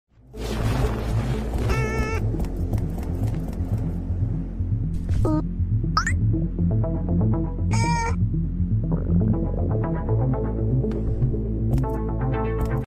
Boyfriend Stomach Growls